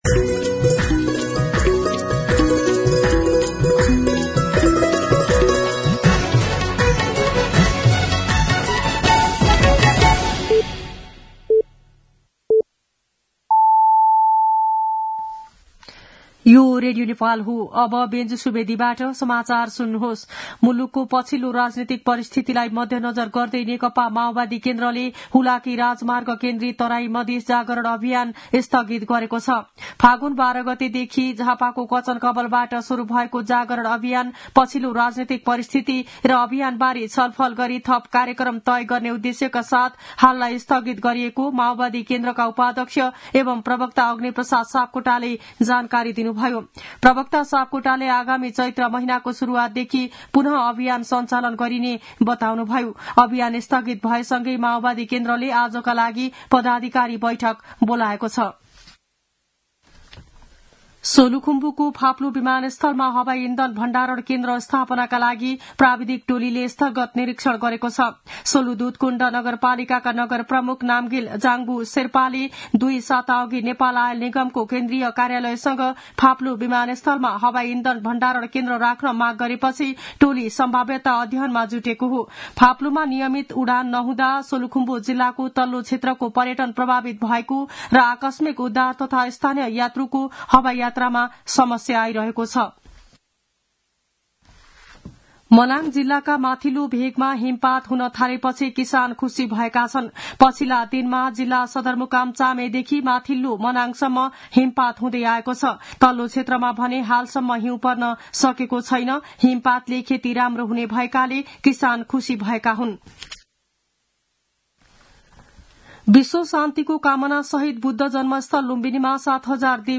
मध्यान्ह १२ बजेको नेपाली समाचार : २६ फागुन , २०८१